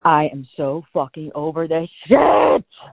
• When you call, we record you making sounds. Hopefully screaming.
You might be unhappy, terrified, frustrated, or elated. All of these are perfectly good reasons to call and record yourself screaming.